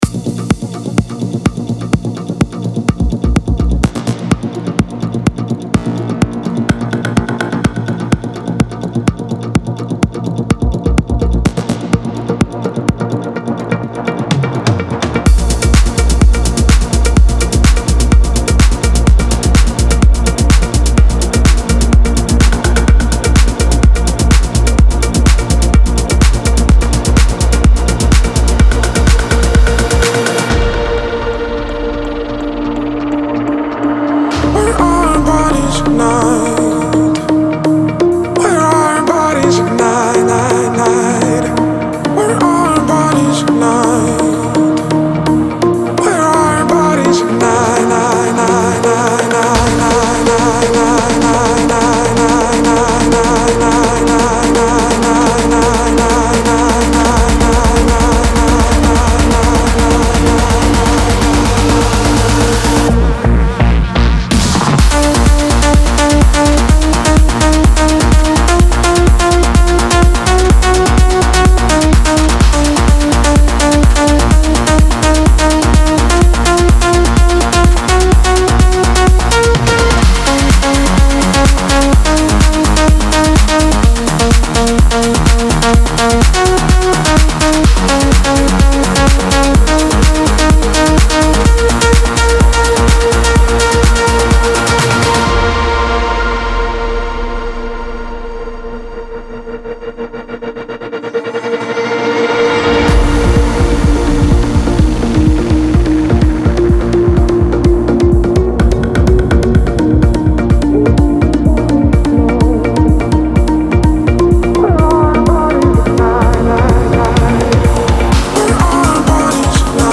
This song is heavily inspired by the Future Rave movement.
melodic twist in the drop.